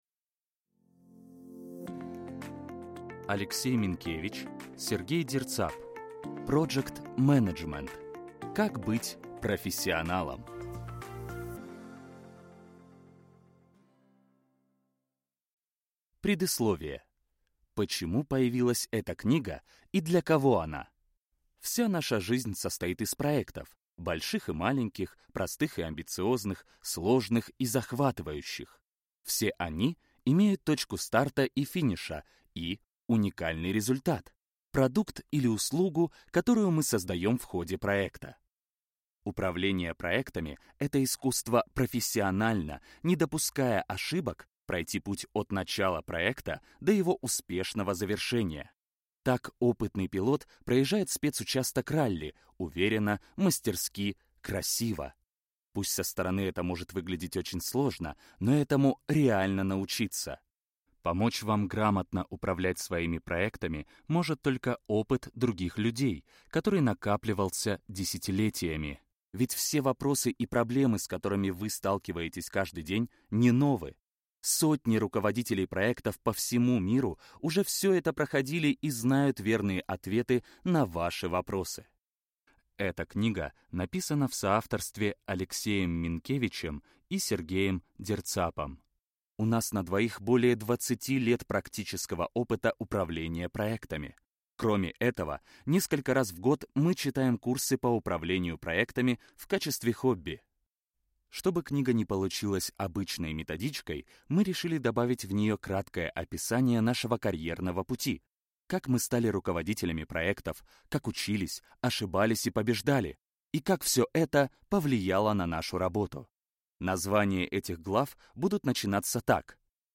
Аудиокнига Проджект-менеджмент. Как быть профессионалом | Библиотека аудиокниг